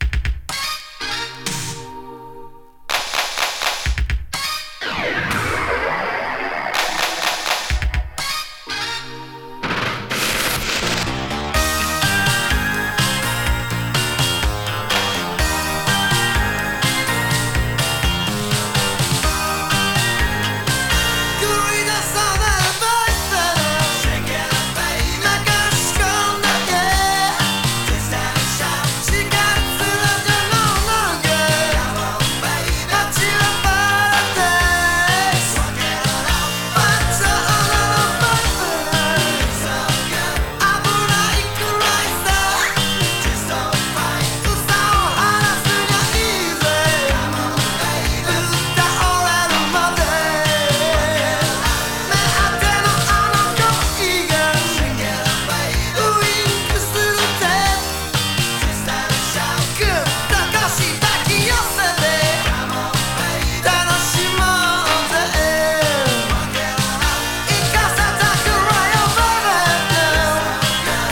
カヴァー
A面は日本語詞でB面は英語詩。